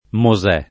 mo@$+] (non Mosé) pers. m. — antiq. Moisè [moi@$+]: Per Moisè, per profeti e per salmi [per mo-i@$, per prof$ti e pper S#lmi] (Dante); San Moisè, chiesa a Venezia — sim. il cogn. Moisè